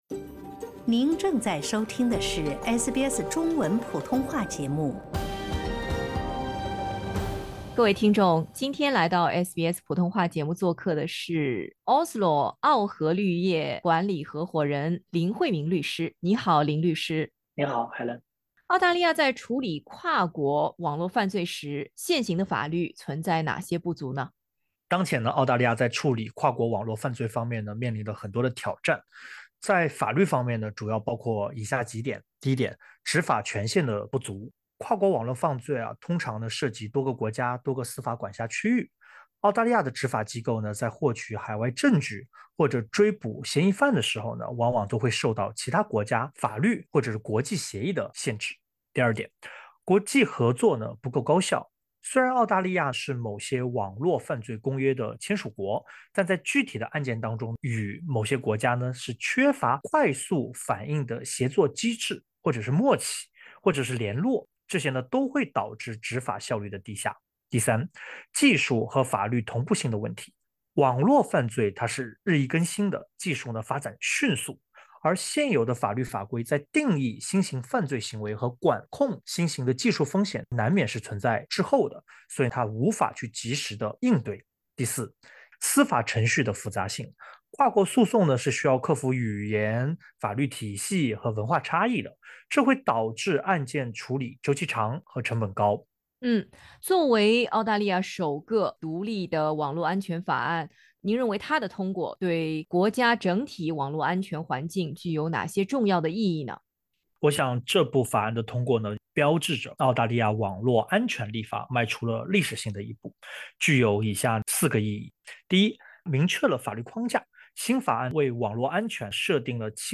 首部独立网络安全法案的通过能否切实提升澳大利亚在打击网络犯罪方面的能力？请点击音频，收听采访！